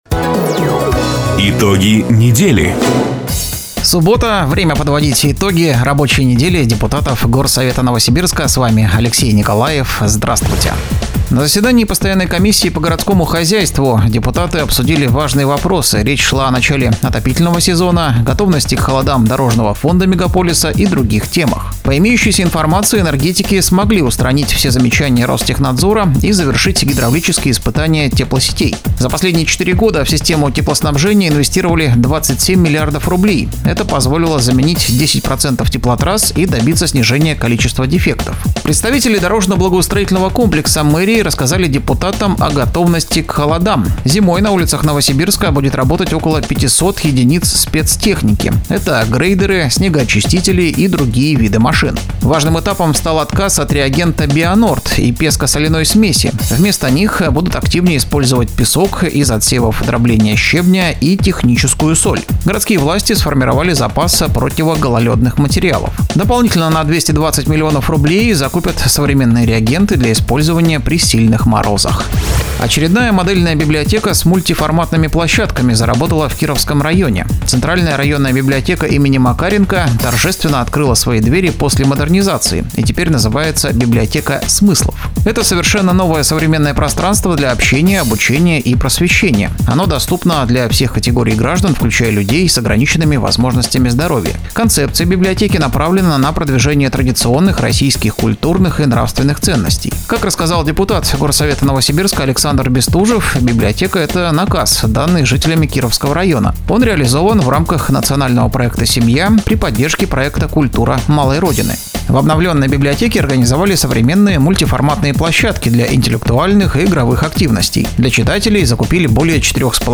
Запись программы "Итоги недели", транслированной радио "Дача" 11 октября 2025 года
Упомянутые депутаты:  Бестужев Александр Владимирович  / Гуща Роман Александрович